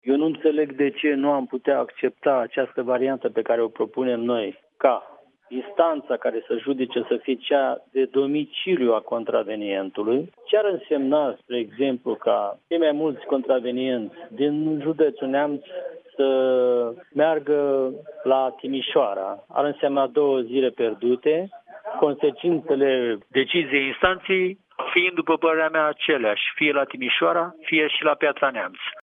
Inițiatorul acestui proiect, deputatul PSD, Ioan Munteanu, a declarat la Europa FM că proiectul este în avantajul şoferilor, care nu ar trebui să se mai meargă în alt oraş pentru proces, în cazul în care nu a existat o vătămare corporală.